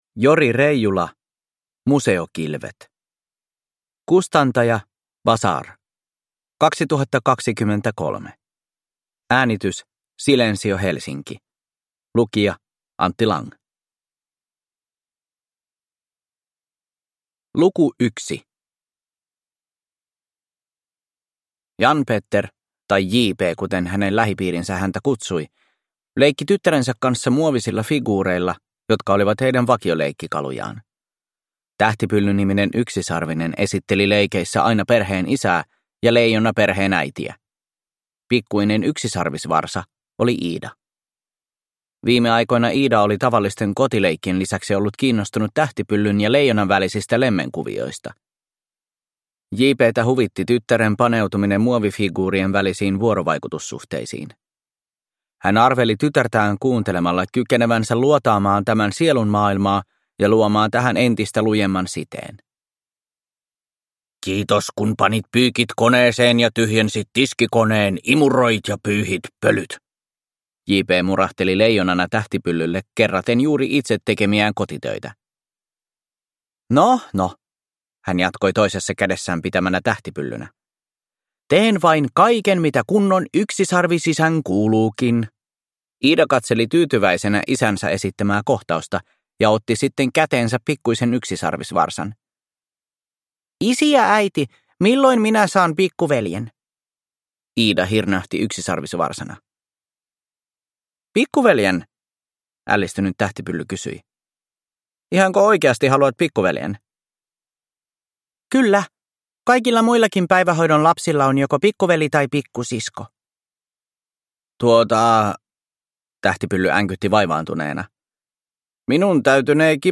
Museokilvet – Ljudbok